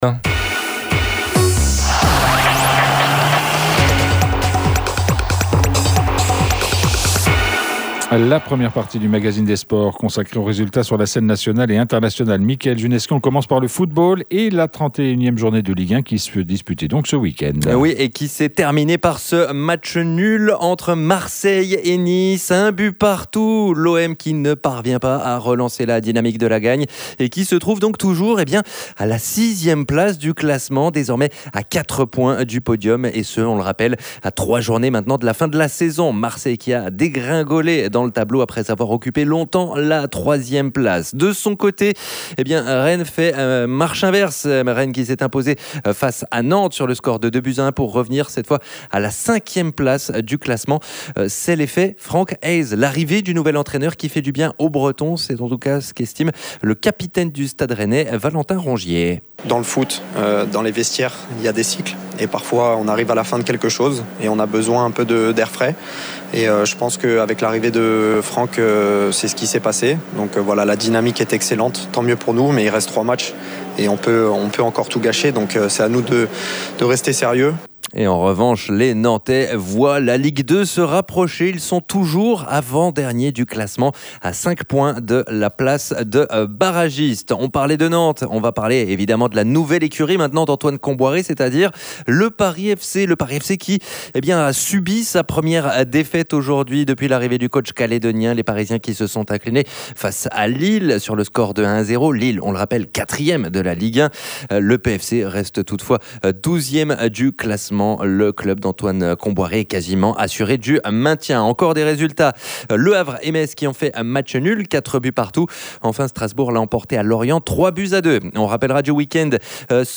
Nous recevions dans nos studios